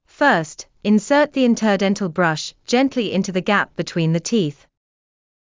ﾌｧｰｽﾄ ｲﾝｻｰﾄ ｼﾞ ｲﾝﾀｰﾃﾞﾝﾀﾙ ﾌﾞﾗｯｼ ｼﾞｪﾝﾄﾘｰ ｲﾝﾄｩ ｻﾞ ｷﾞｬｯﾌﾟ ﾋﾞﾄｩｳｨｰﾝ ｻﾞ ﾃｨｰｽ